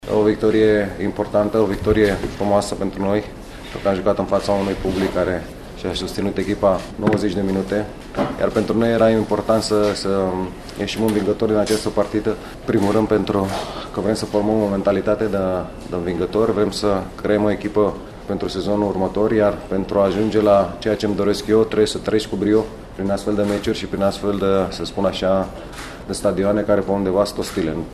În sesiunea de declarații, de la finalul jocului, antrenorul oaspeților Adrian Mutu a remarcat importanța victoriei, pe plan mental, pentru jucătorii lui. Mutu a interpretat experiența de la Arad ca pe o ”rodare” a rezistenței psihice și fizice în perspectiva sezonului ce urmează: